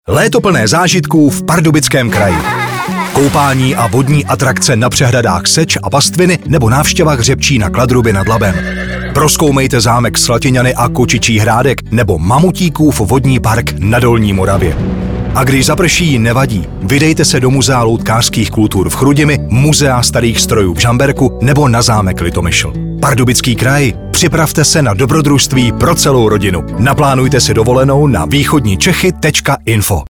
Rozhlasová kampaň